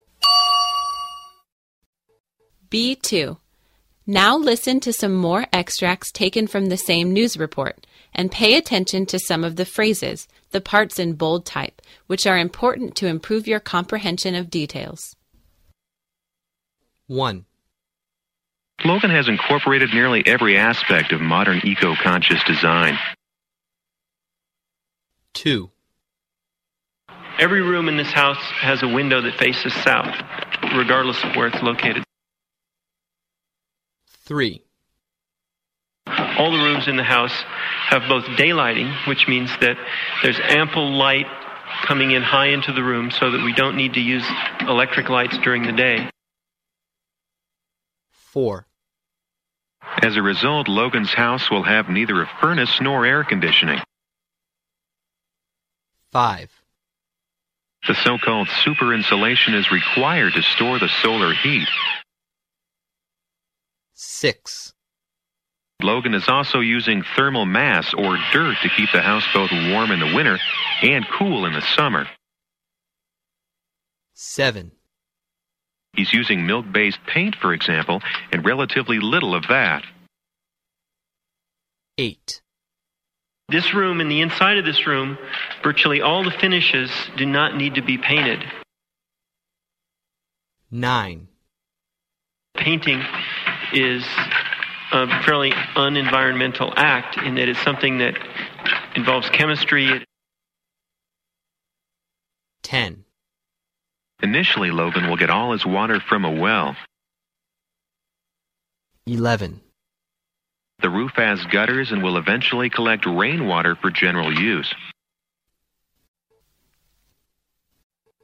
B2. Now listen to some more extracts taken from the same news report, and pay attention to some of the phrases, the parts in bold type, which are important to improve your comprehension of details.